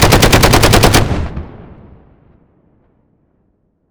machineout.wav